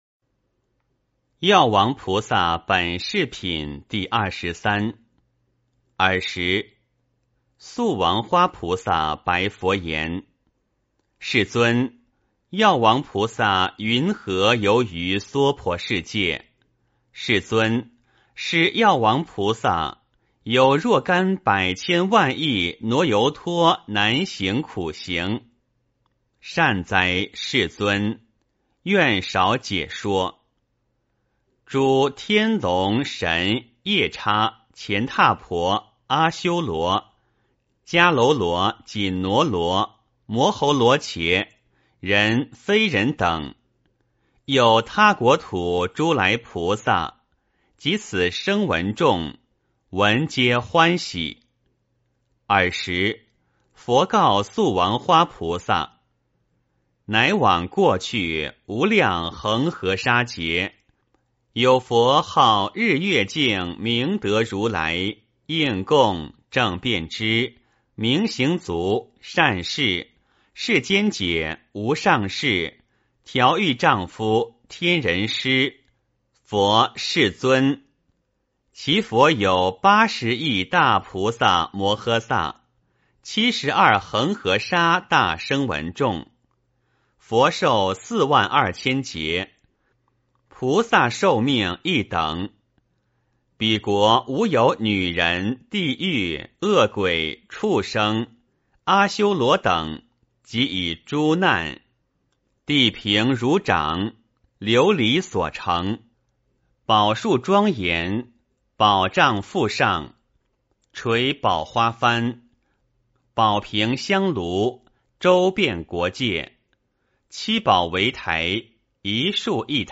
法华经-药王菩萨本事品第二十三 - 诵经 - 云佛论坛